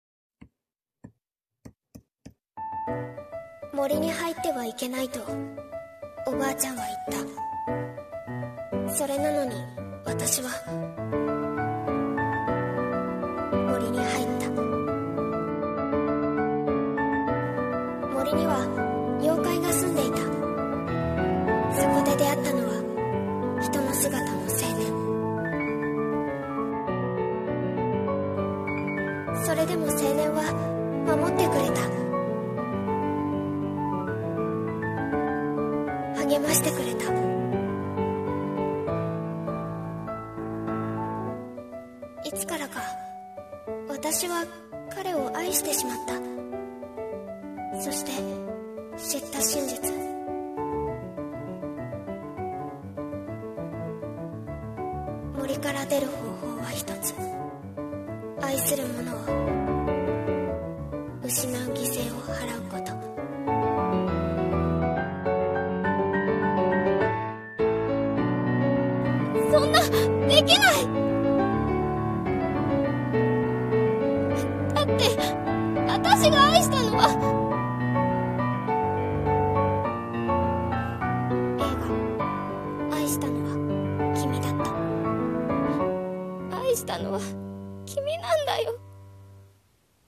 【映画予告風声劇】愛したのは君だった